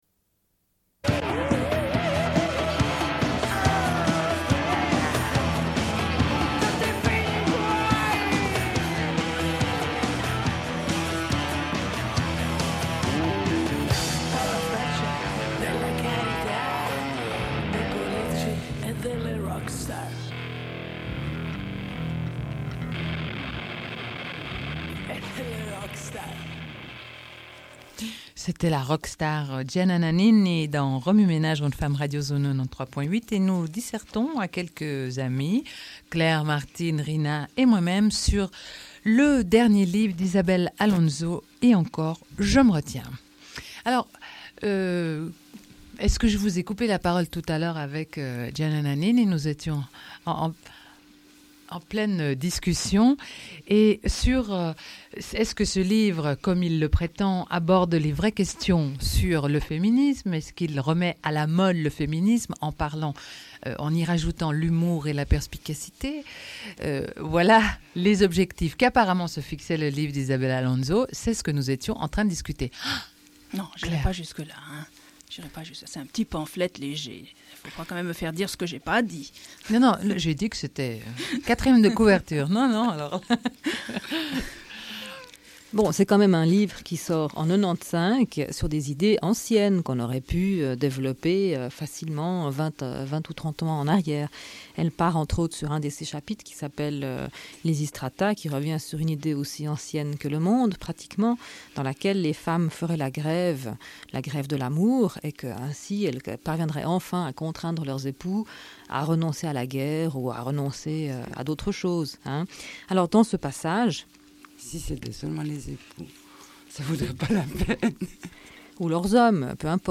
Deuxième partie d'émission, rencontre avec Les Reines prochaines, à la Cave 12 le 14 décembre 1995.
Radio Enregistrement sonore